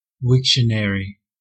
Wiktionary (UK: /ˈwɪkʃənəri/ , WIK-shə-nər-ee; US: /ˈwɪkʃənɛri/